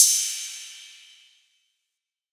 DDW Cymbal 1.wav